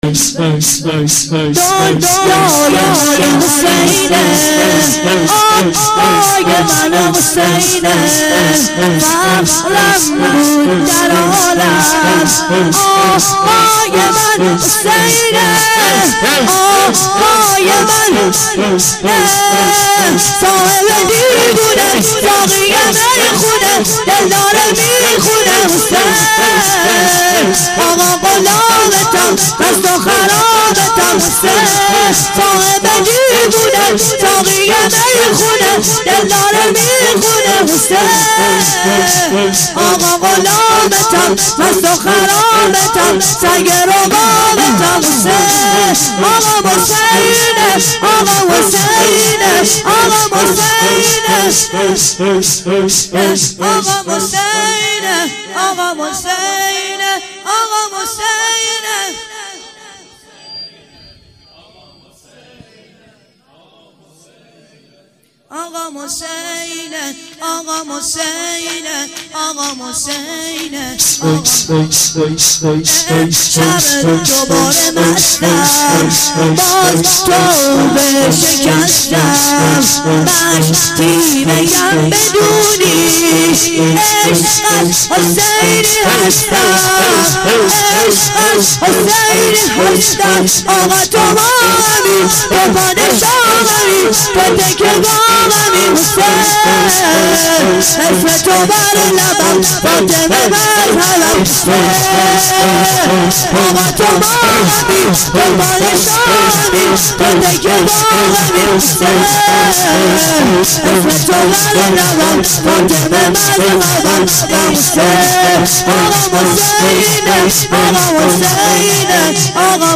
شور - دنیا یه من حسینیه